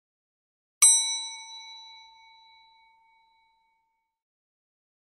دانلود صدای زنگ آسانسور 1 از ساعد نیوز با لینک مستقیم و کیفیت بالا
جلوه های صوتی